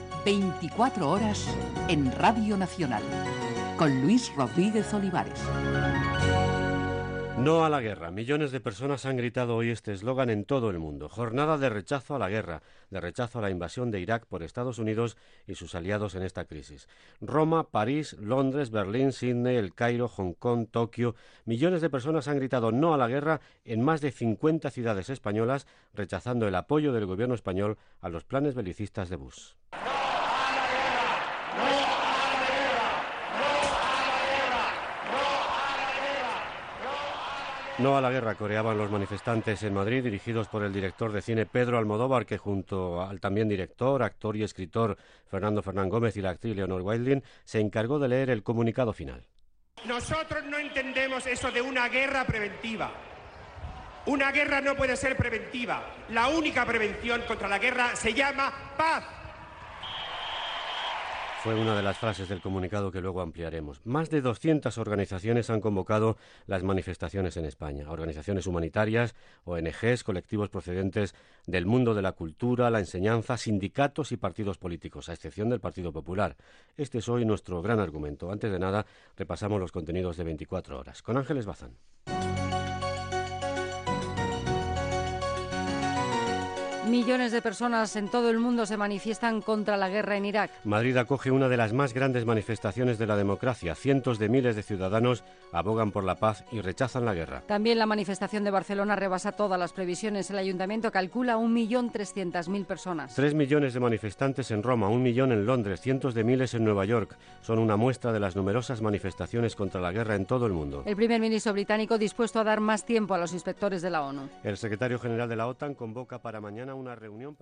Careta del programa, manifestacions en tot el món contra la guerra d'Iraq impulsades pel Fòrum Social Mundial
Informatiu